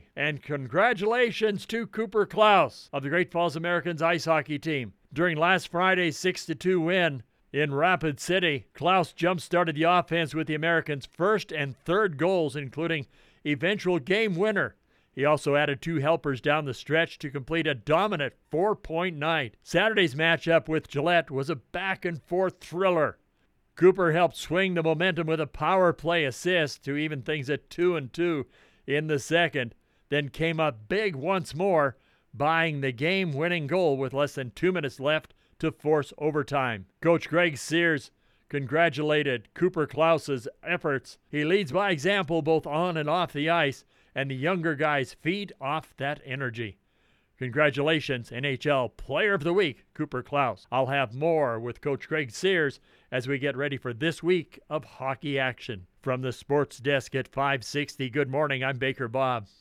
The Weekly Radio Interview on 560AM KMON in Great Falls can be heard every Wednesday, Thursday and Friday mornings during the Hourly Sports Report (6:15AM (MST), 7:15AM (MST), and 8:15AM (MST) live.